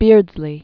(bîrdzlē), Aubrey Vincent 1872-1898.